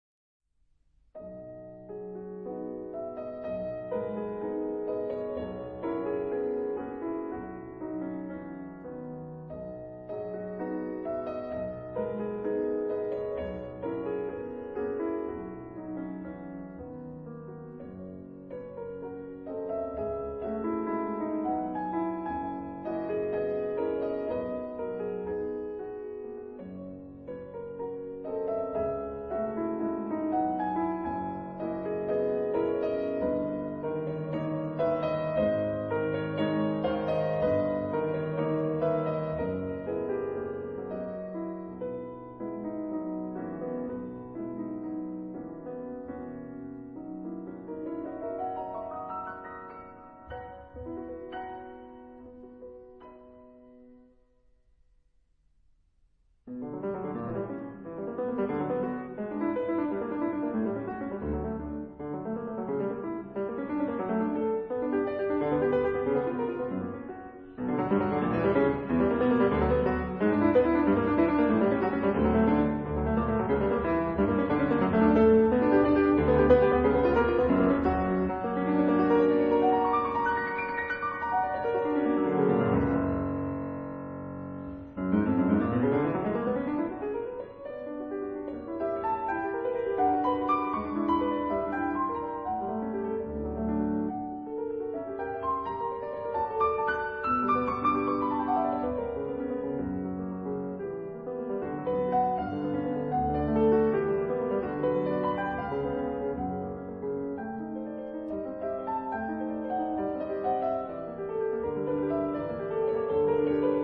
反而，收斂一下，帶了點陽光的感覺進來。